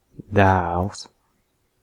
Ääntäminen
IPA: [ˈpoikɑ] IPA: /ˈpoj.kɑ/